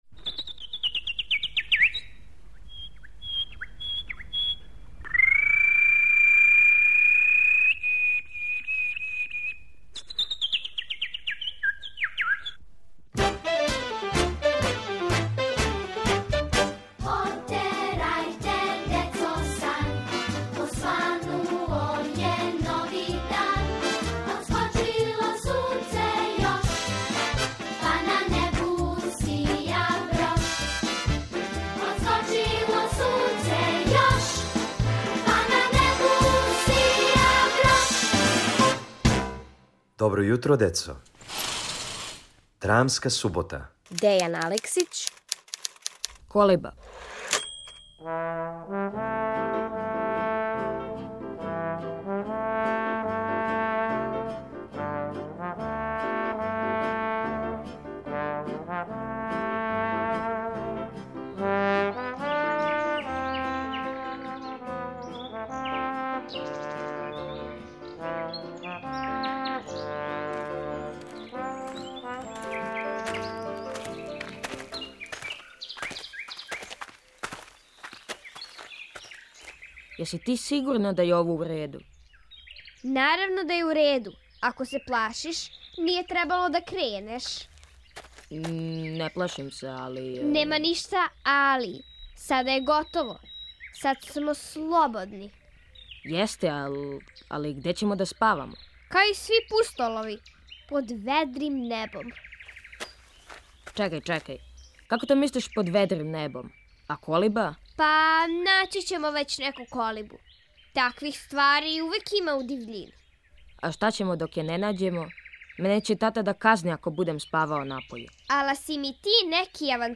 У овој краткој драми Дејана Алексића сазнајте како да направите колибу без интернет домета - или не...